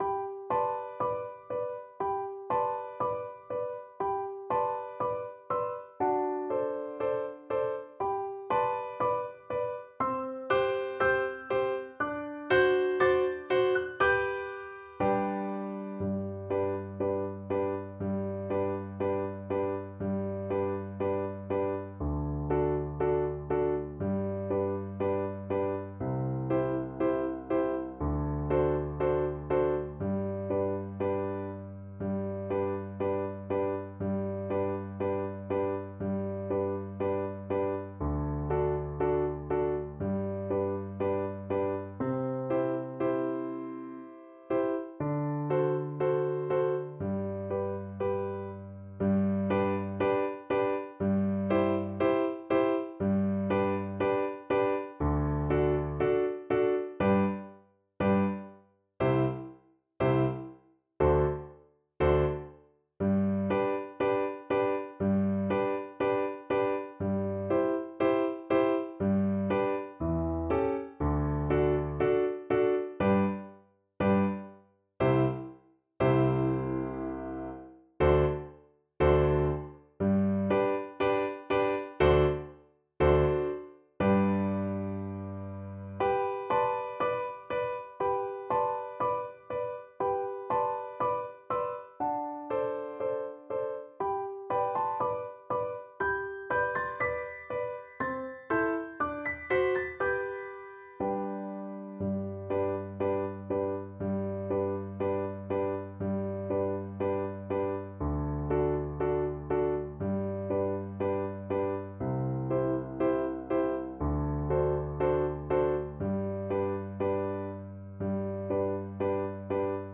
G major (Sounding Pitch) (View more G major Music for Cello )
4/4 (View more 4/4 Music)
Moderato
Cello  (View more Easy Cello Music)